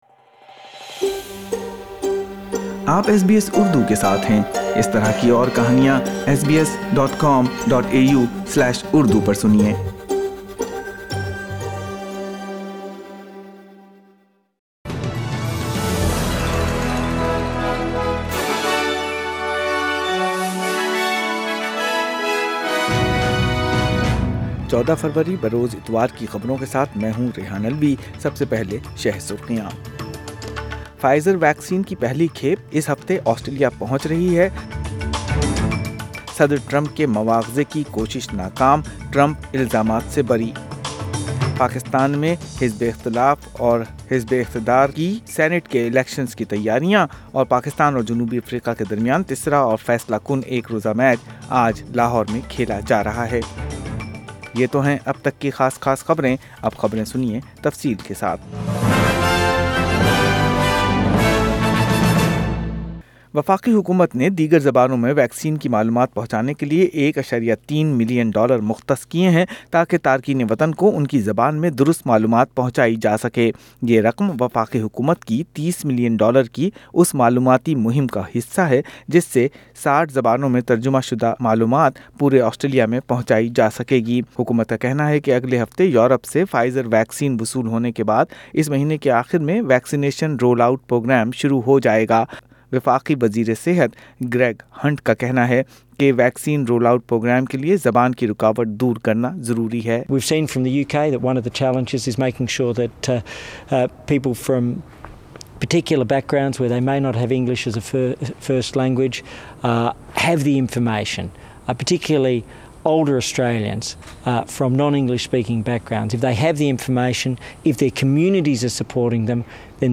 اردو خبریں اتوار 14 فروری 2021